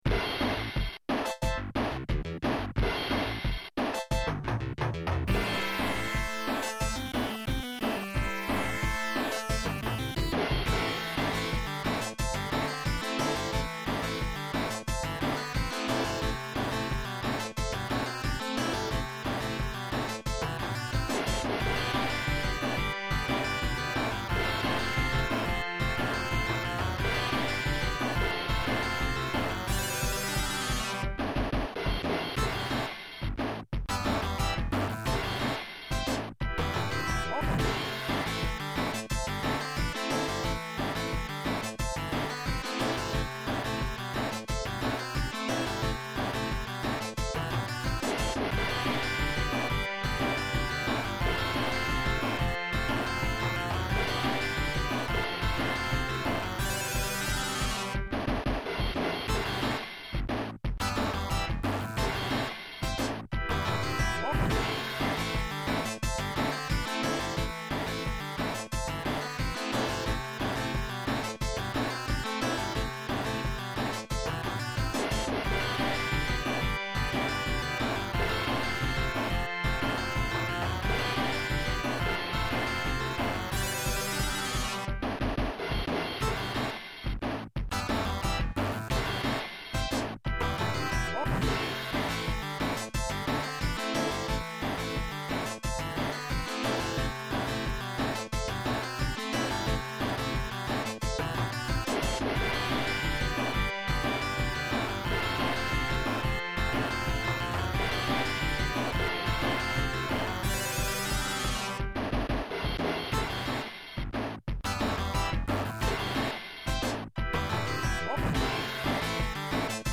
All of this music is from the arcade version of the game.